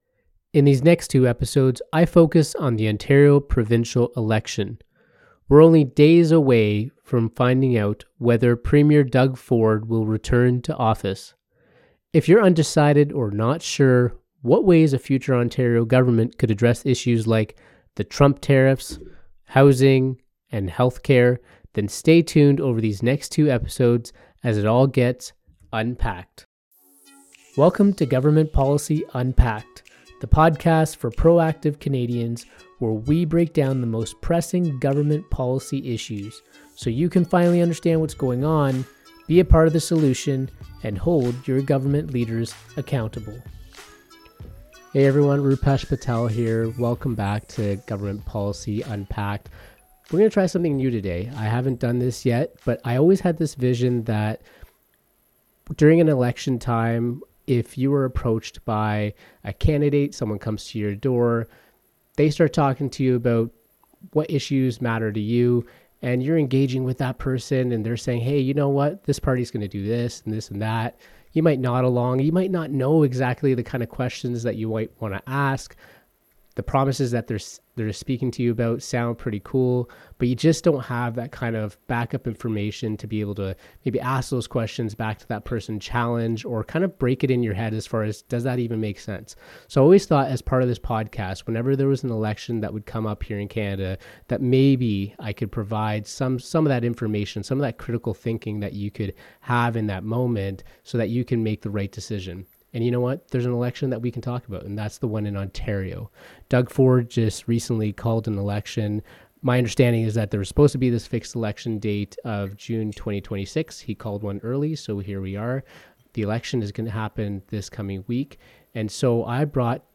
They explore how civic responsibility extends beyond just voting, encouraging listeners to critically analyze political promises and their implications. The conversation also delves into the impact of Trump tariffs on Ontario's economy and the need for citizens to actively communicate with their representatives to influence policy decisions.